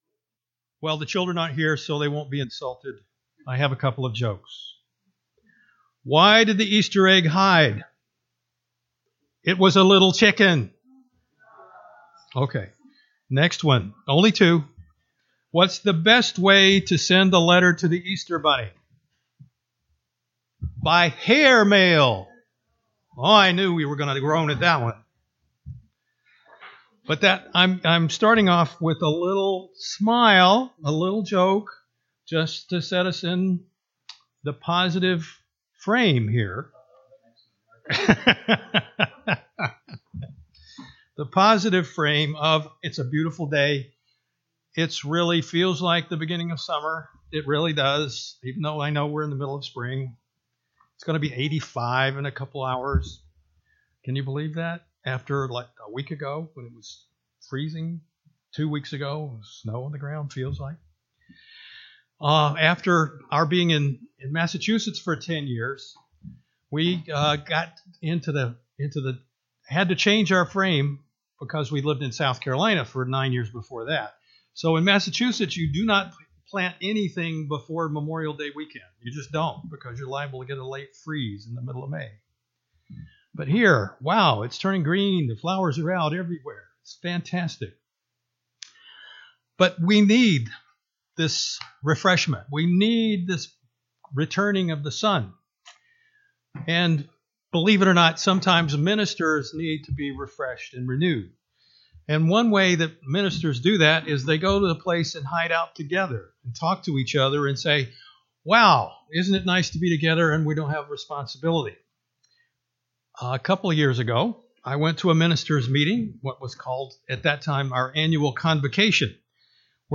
In this sermon, the speaker reflects on the transformative power of storytelling to inspire personal growth and hope.